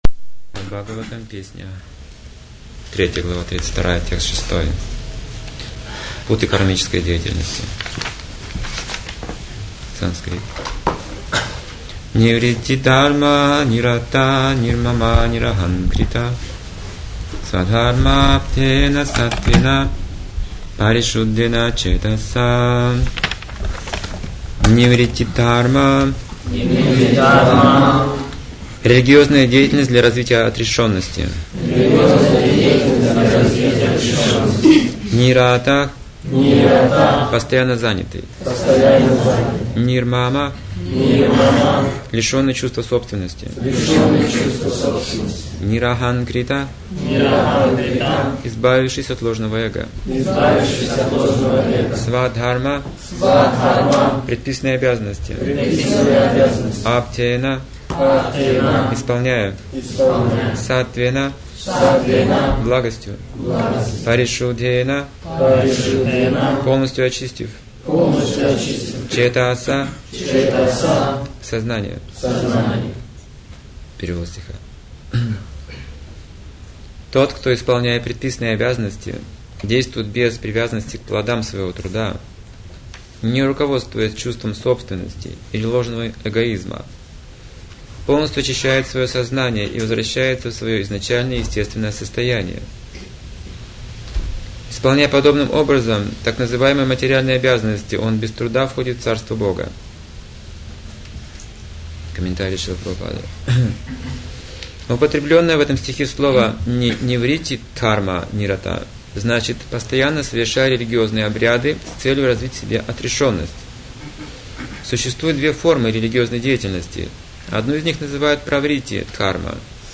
ШБ 3.32.6 (xx.06.2003, Липецк)